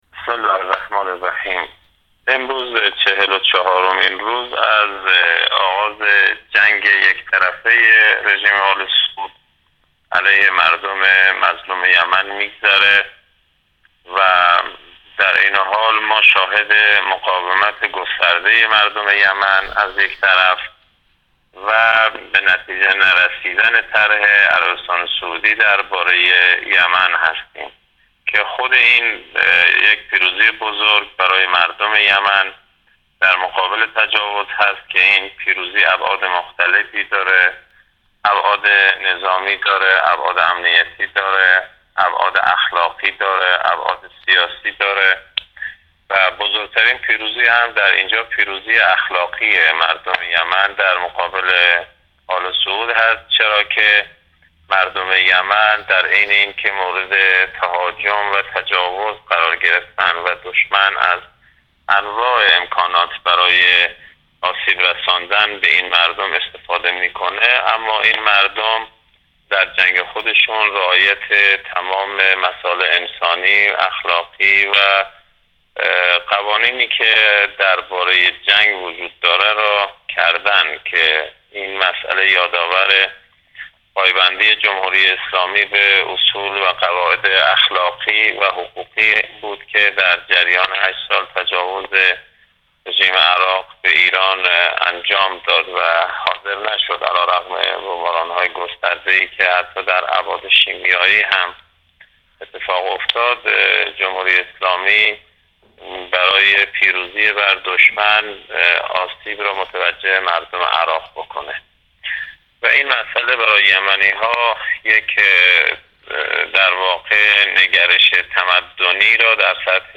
محورهای سخنان کارشناس امروز